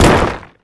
rock_impact_small_hit_03.wav